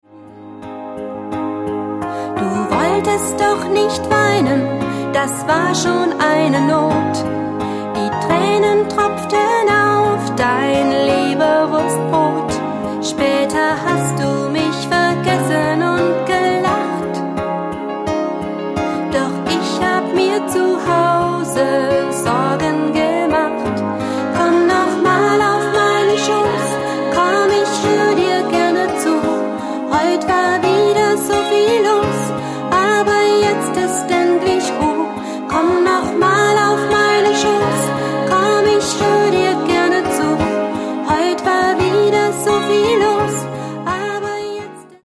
Die schönsten Gute-Nacht-Lieder
• Sachgebiet: Kinderlieder